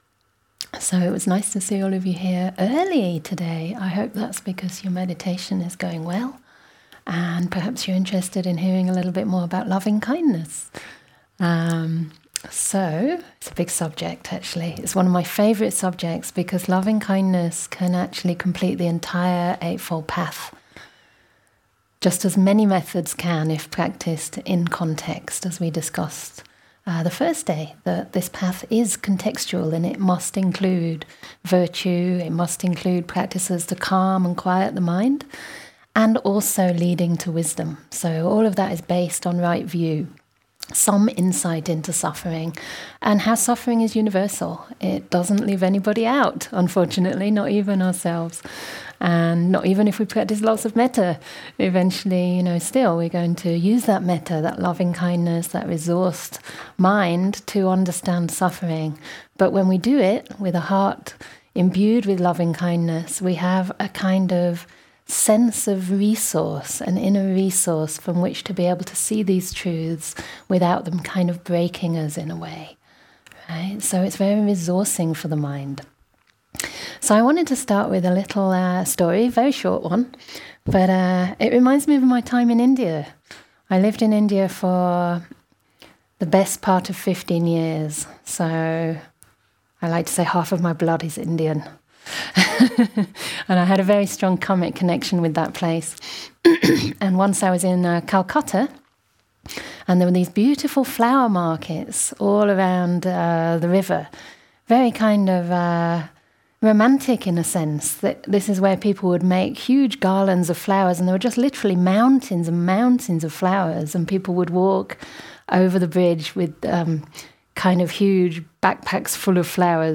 And this talk is about how to do it.